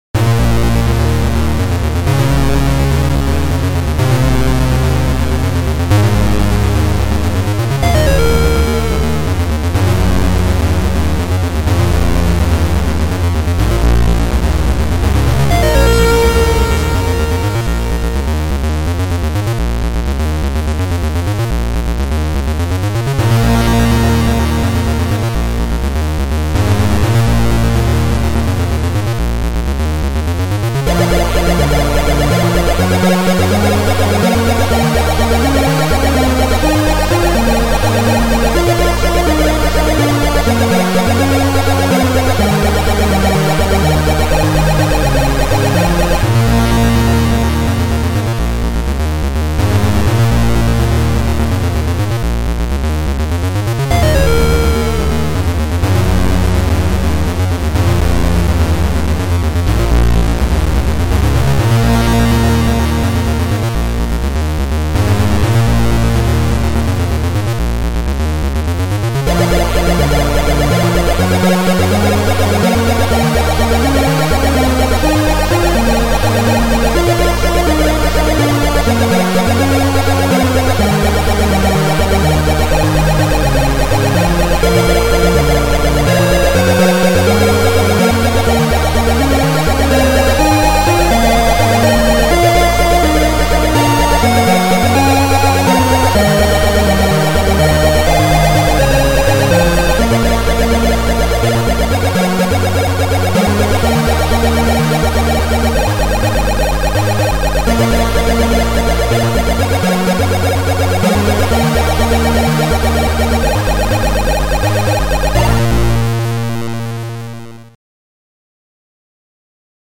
Sound Format: Noisetracker/Protracker
Chip Music Pack